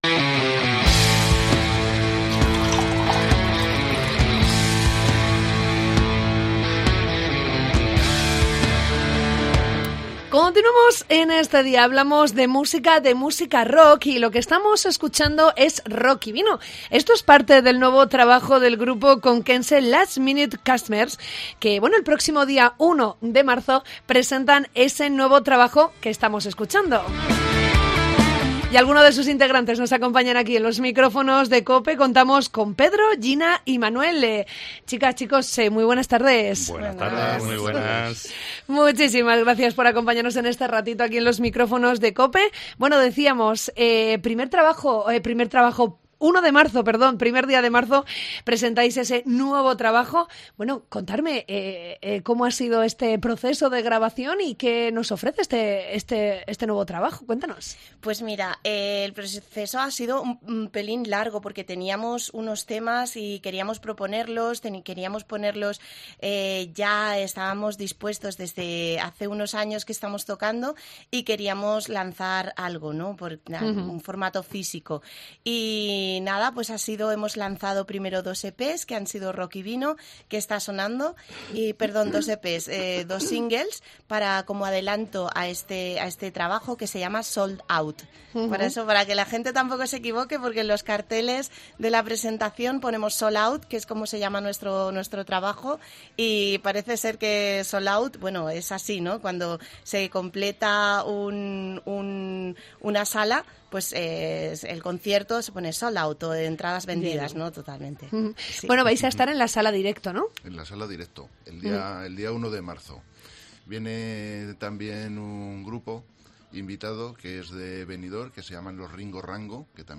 El grupo de rock conquense ha pasado por los micrófonos de COPE donde ha explicado los detalles de su último disco
ctv-nv9-entrevista-con-last-minute-customers